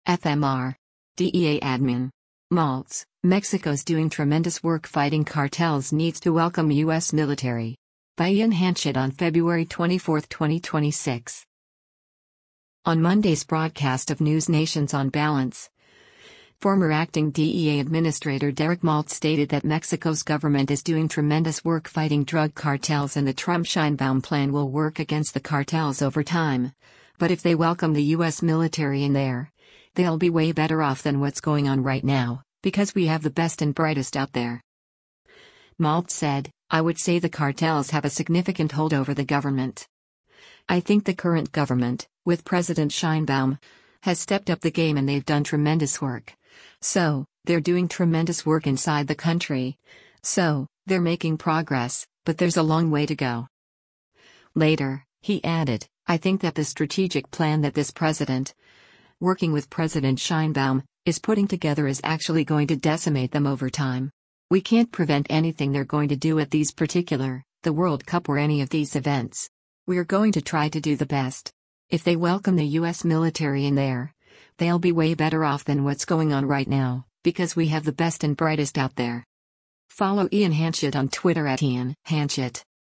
On Monday’s broadcast of NewsNation’s “On Balance,” former acting DEA Administrator Derek Maltz stated that Mexico’s government is “doing tremendous work” fighting drug cartels and the Trump-Sheinbaum plan will work against the cartels over time, but “If they welcome the U.S. military in there, they’ll be way better off than what’s going on right now, because we have the best and brightest out there.”